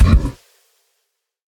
Minecraft Version Minecraft Version snapshot Latest Release | Latest Snapshot snapshot / assets / minecraft / sounds / mob / camel / dash6.ogg Compare With Compare With Latest Release | Latest Snapshot